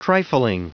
Prononciation du mot trifling en anglais (fichier audio)
Prononciation du mot : trifling